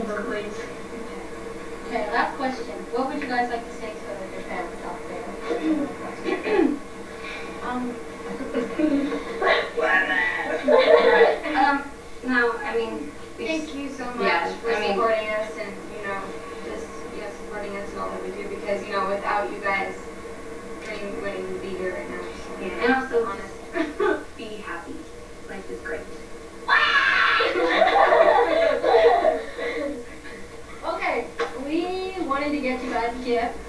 Interview: To fans.wav